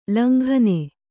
♪ langhane